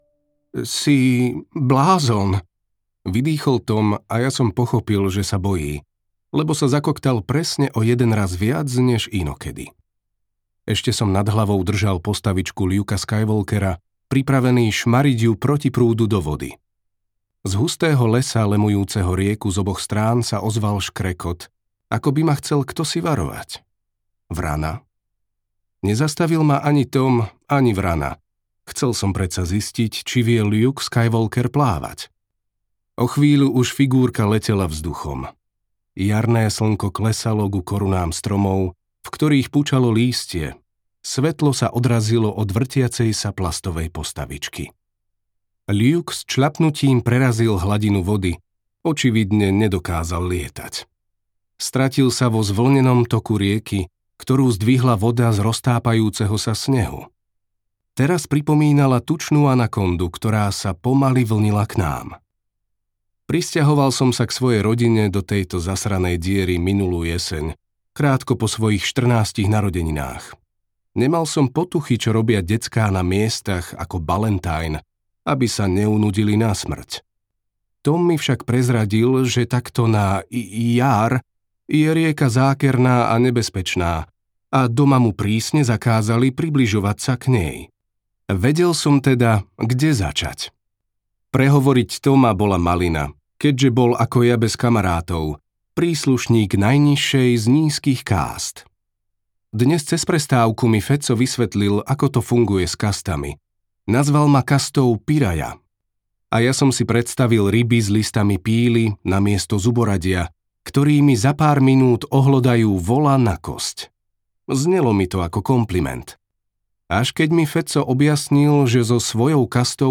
Nočný dom audiokniha
Ukázka z knihy